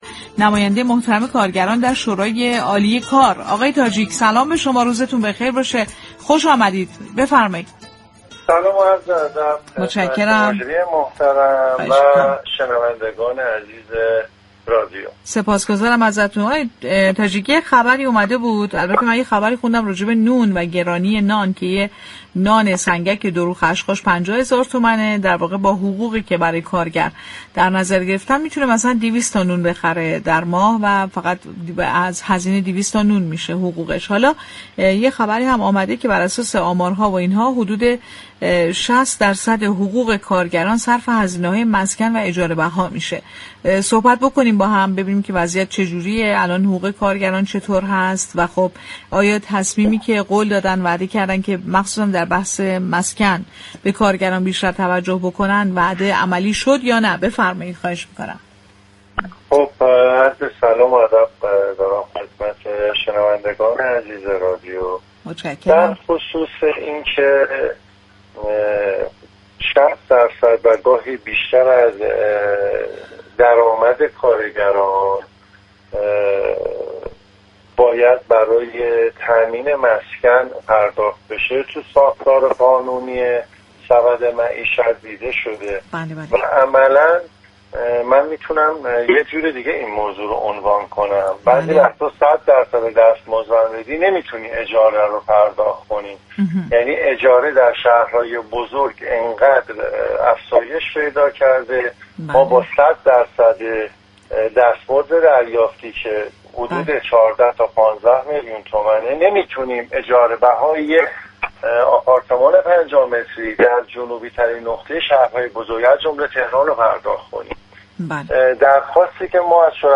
نماینده كارگران در شورای عالی كار در گفت و گو با رادیو تهران، با اشاره به افزایش شدید هزینه مسكن و معیشت در شهرهای بزرگ گفت كه كارگران با دستمزد فعلی قادر به تأمین زندگی نیستند و خواستار بازنگری فوری دستمزدها و اجرای طرح‌های مسكن برای كارگران شد.